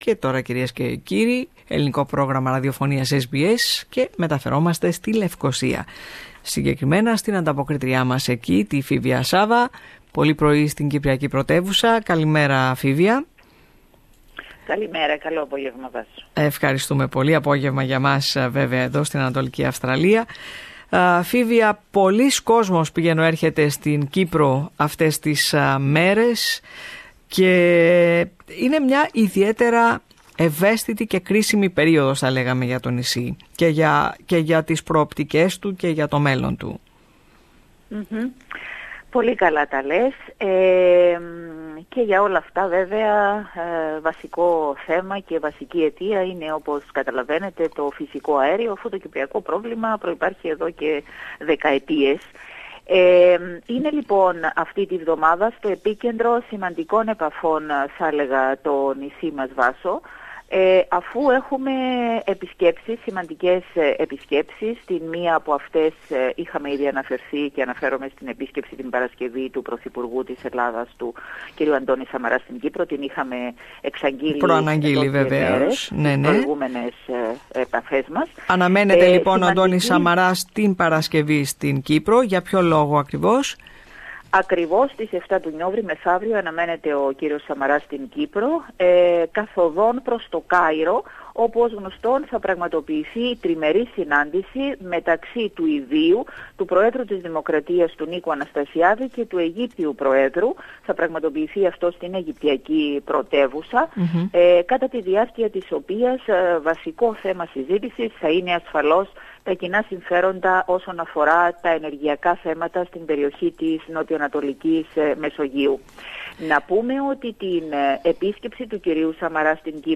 weekly report from Cyprus